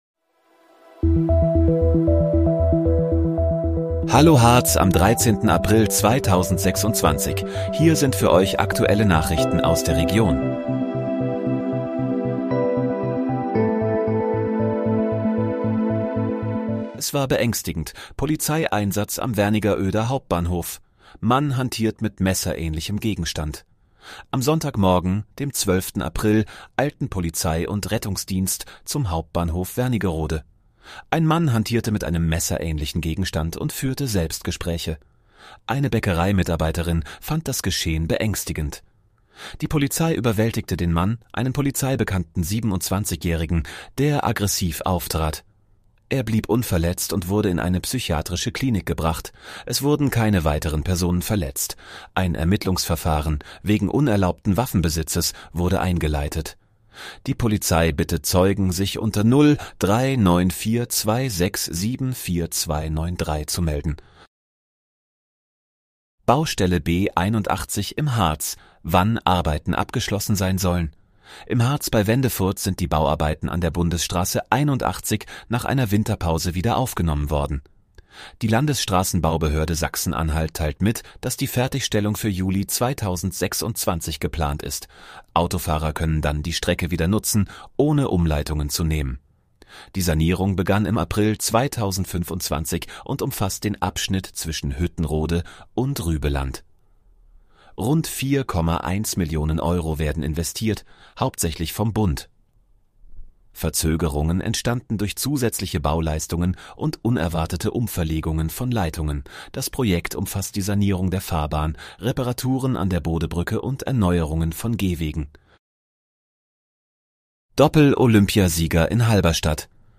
Hallo, Harz: Aktuelle Nachrichten vom 13.04.2026, erstellt mit KI-Unterstützung